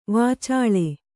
♪ vācāḷe